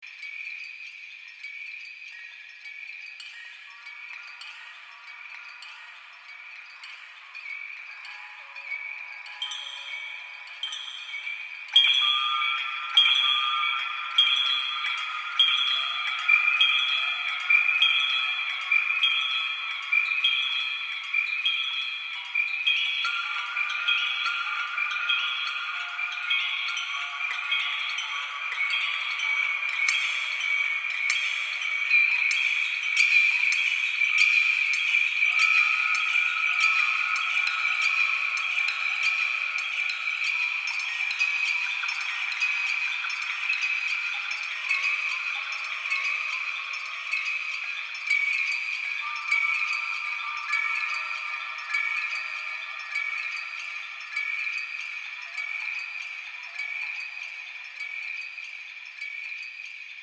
Łańcuchy (nieużywany dźwięk).ogg
Łańcuchy_(nieużywany_dźwięk).ogg